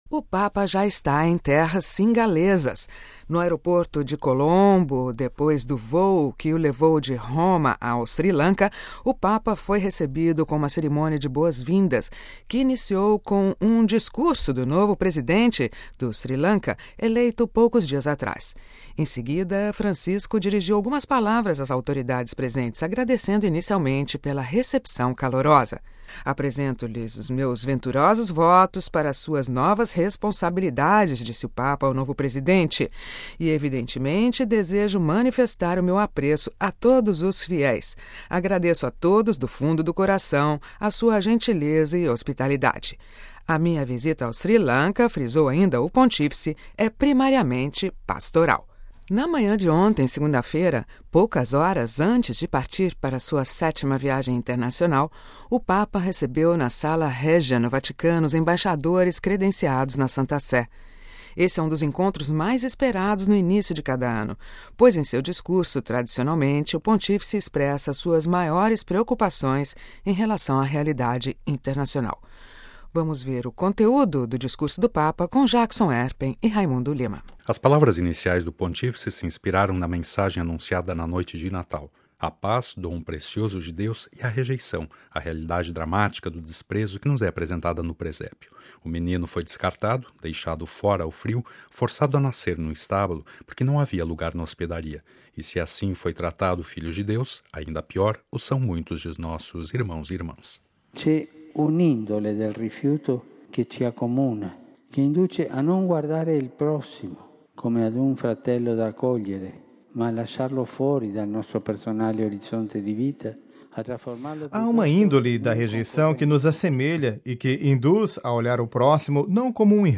Boletim da Rádio Vaticano